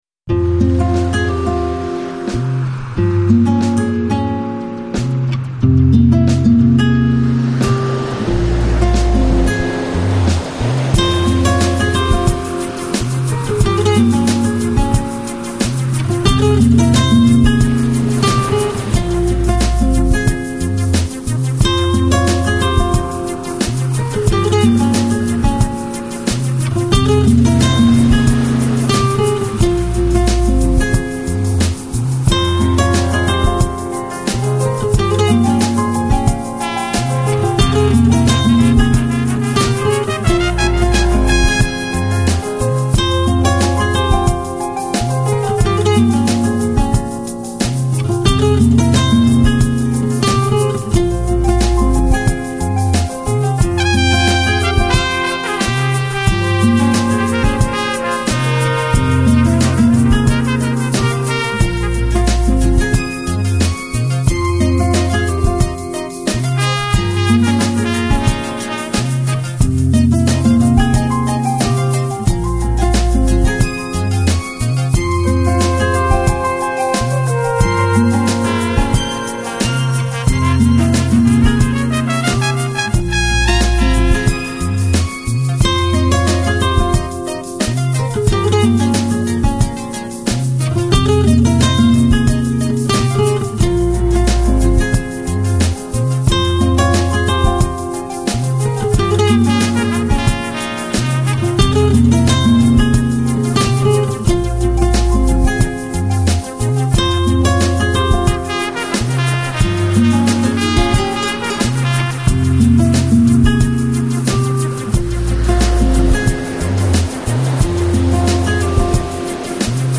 Жанр: Lounge, Chill Out, Balearic, Downtempo